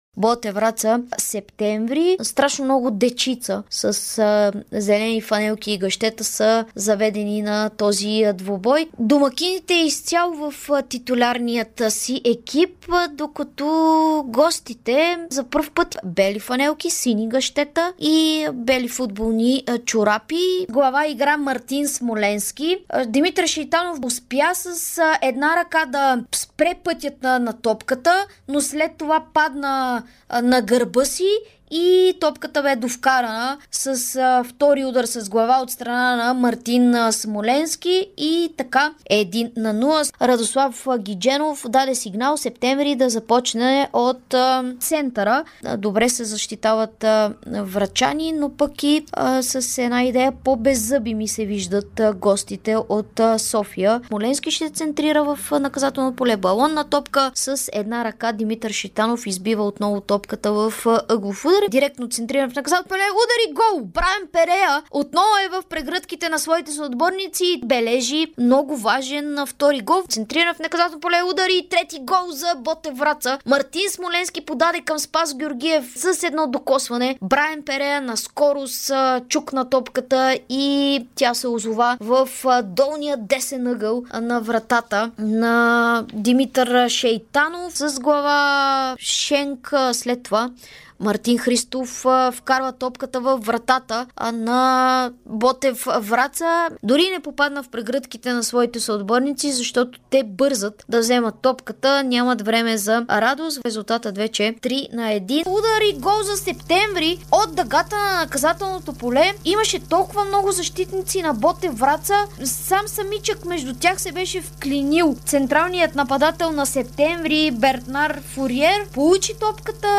Какво чухме по Дарик радио от родните футболни терени от изминалия 32-ри кръг на Първа лига, в който имаше награждаване на шампиона Лудогорец, който триумфира за 14-и път с титлата на страната? Как коментаторите на двубоите по Дарик видяха големия брой голове и малкото публиката по терените от Първа лига?